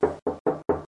敲门
描述：敲打着一扇木质薄门。
标签： 门敲
声道立体声